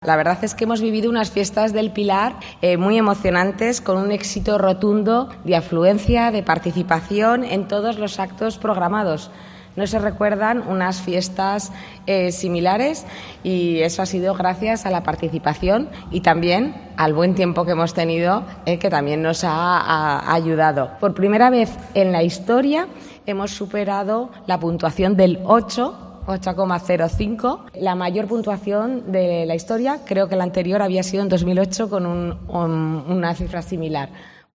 La alcaldesa de Zaragoza, Natalia Chueca, hace balance de las Fiestas del Pilar 2023.